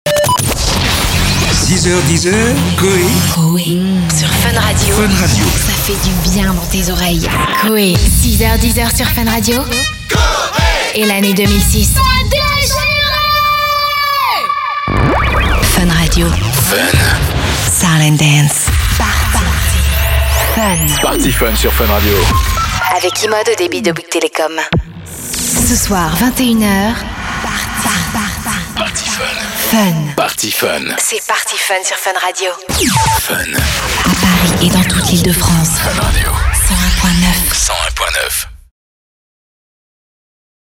Comédienne et chanteuse,je fais des voix régulièrement (doublage,pub,habillage radio et TV) parallèlement à mes activités sur scène.
Sprechprobe: Sonstiges (Muttersprache):